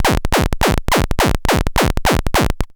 VCS SCRATCH.wav